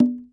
SingleHit_QAS10766.WAV